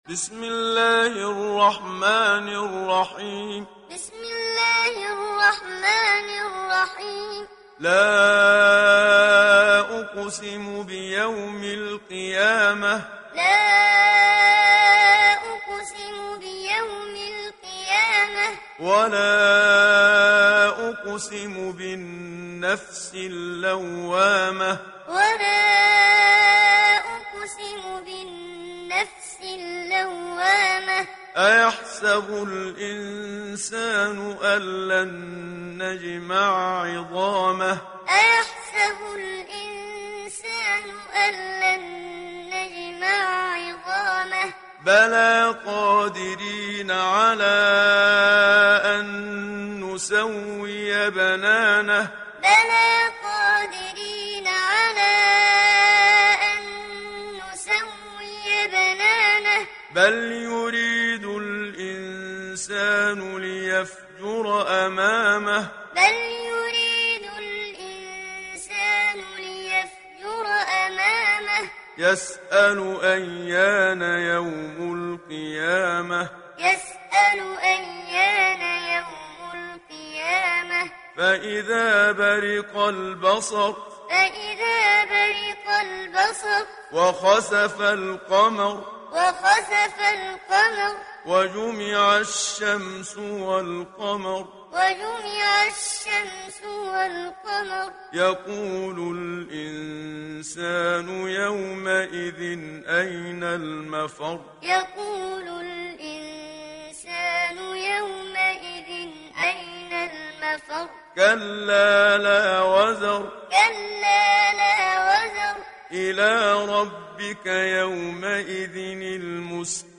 Download Surah Al Qiyamah Muhammad Siddiq Minshawi Muallim